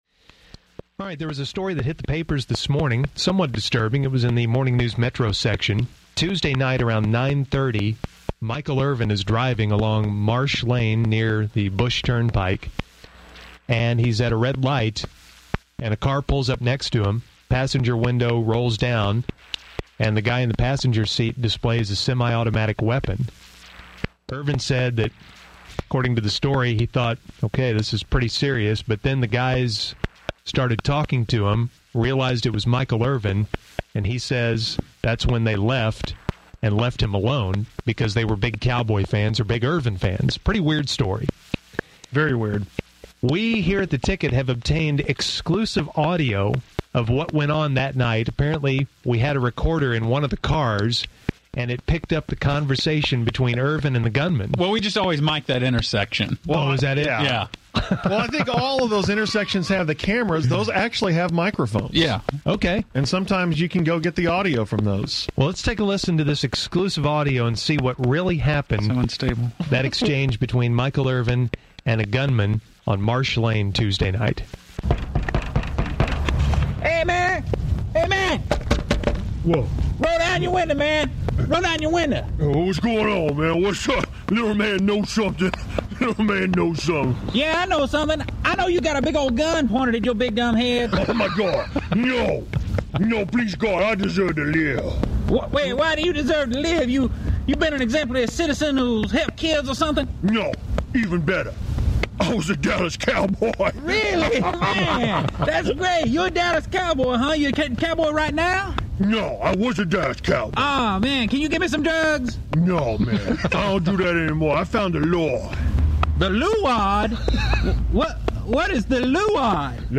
FYI – The audio has some pops in it, my computer decided to run a virus scan in the background while I was recording.